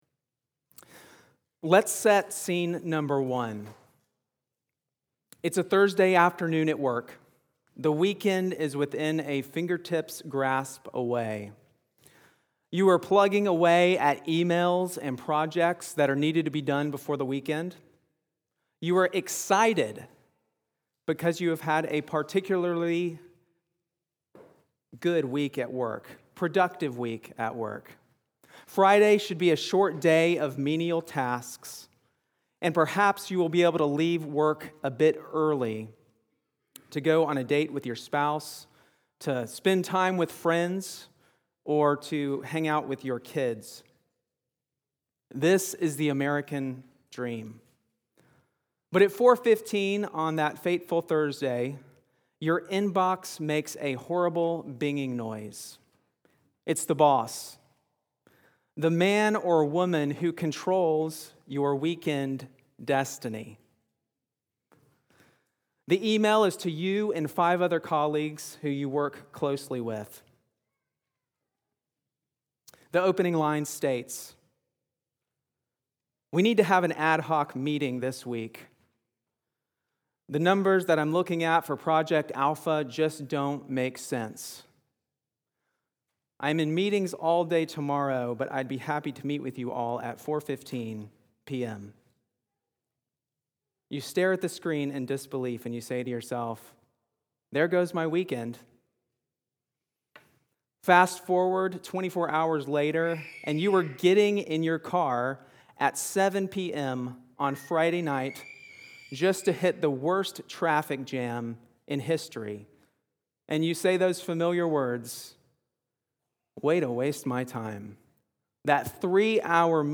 Grace Fellowship Sanford Sermons